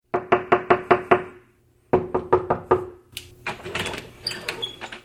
Knock at the door - Стук в дверь
Отличного качества, без посторонних шумов.
356_stuk-v-dver.mp3